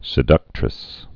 (sĭ-dŭktrĭs)